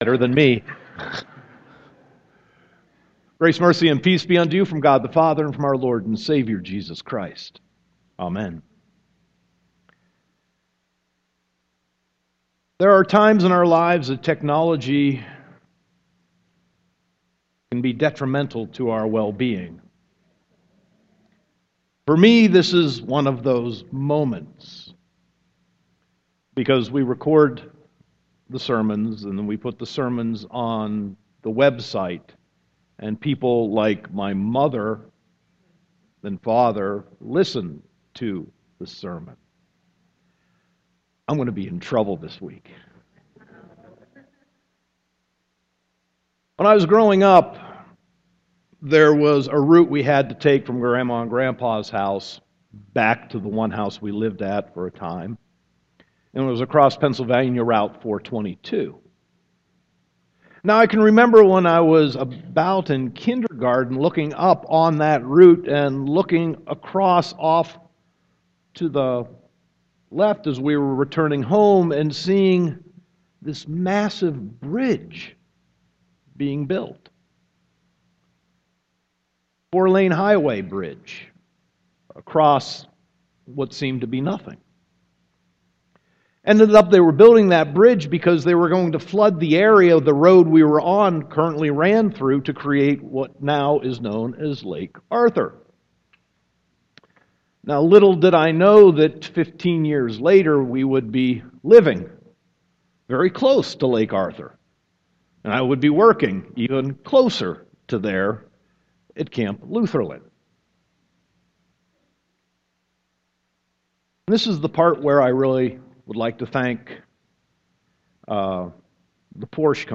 Sermon 5.22.2016